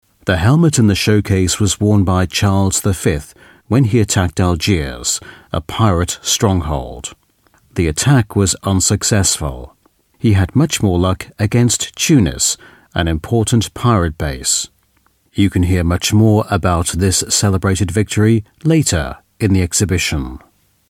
Native Speaker
Englisch (UK)
Audioguides